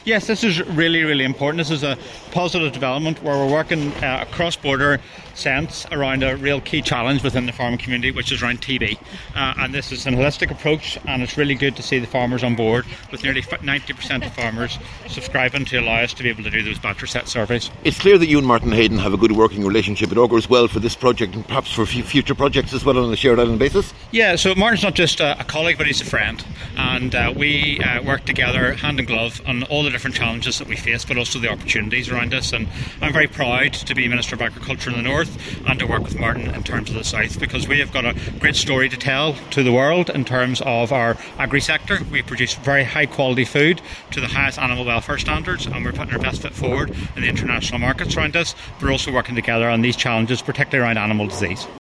Speaking at the launch, Minister Andrew Muir says he and Minister Heydon have a close working relationship……………..